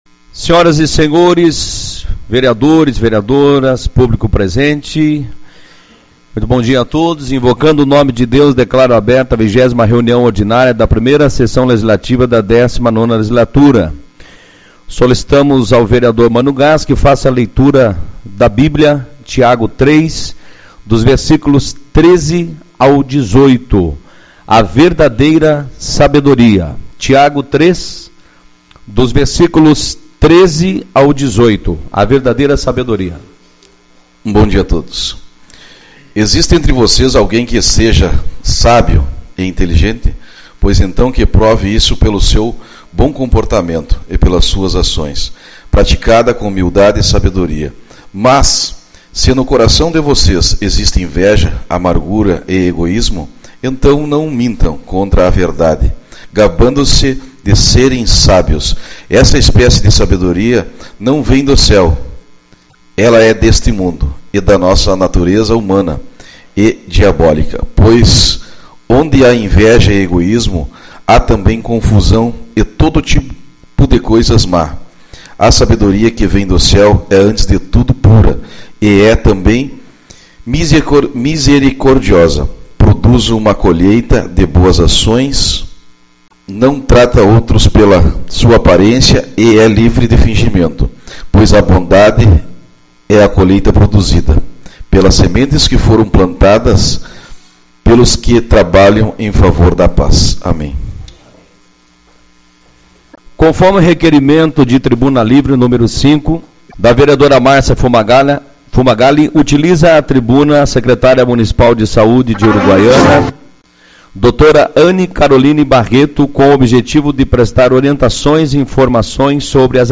Reunião Ordinária